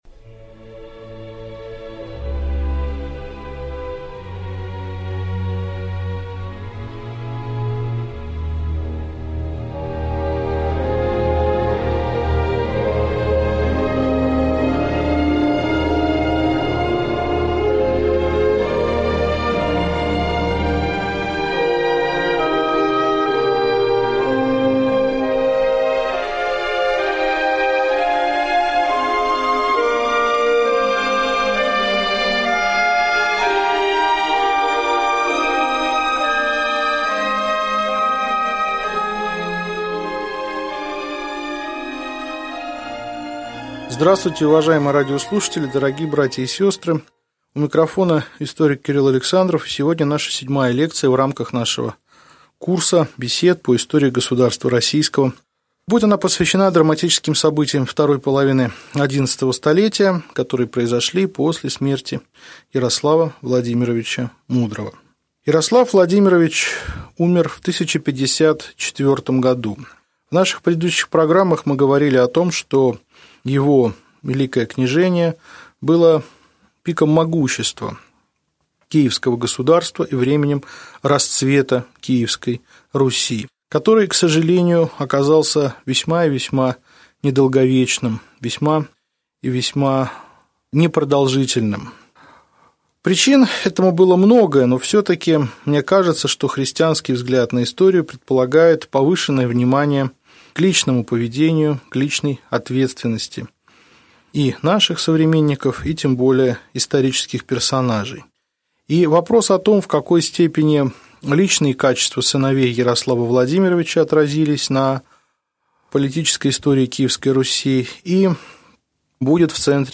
Аудиокнига Лекция 7. Дети и внуки Ярослава Мудрого | Библиотека аудиокниг